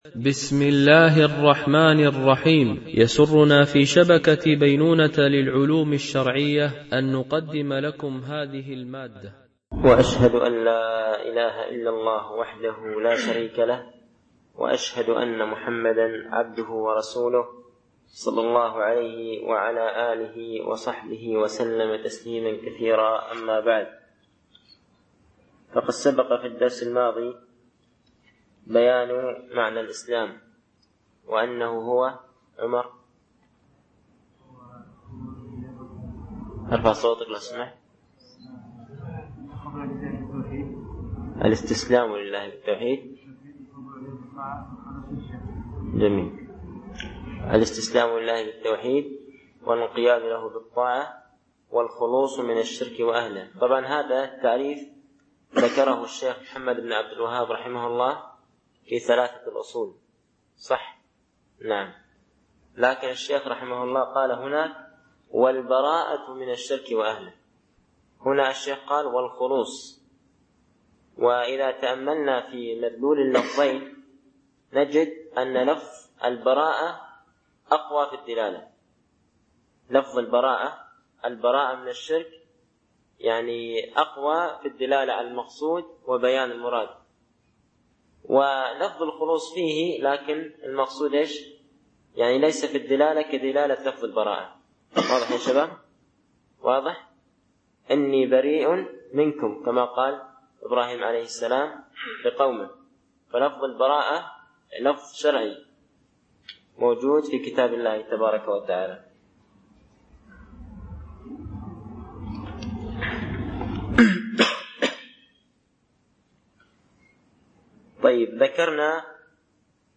شرح أعلام السنة المنشورة ـ الدرس 05 ( ما معنى شهادة أن لا إله إلا الله ؟... )
) الألبوم: شبكة بينونة للعلوم الشرعية التتبع: 5 المدة: 59:21 دقائق (13.62 م.بايت) التنسيق: MP3 Mono 22kHz 32Kbps (CBR)